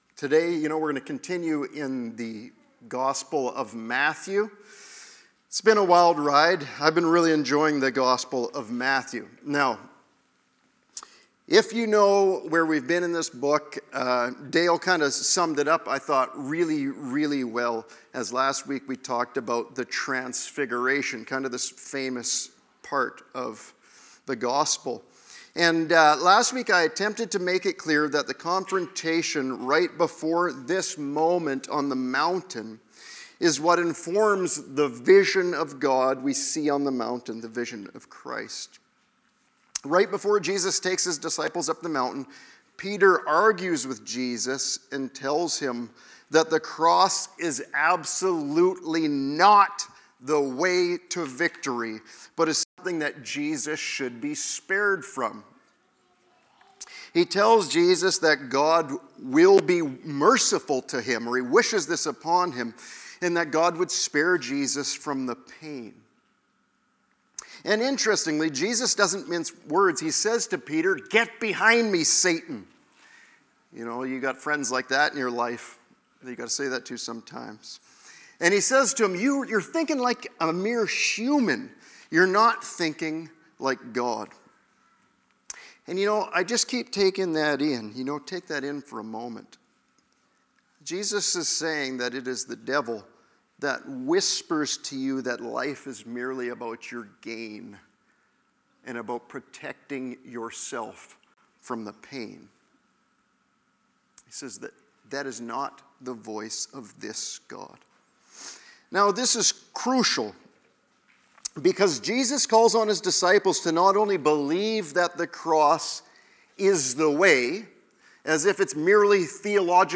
Sermon-Audio-Jan-4-2026.mp3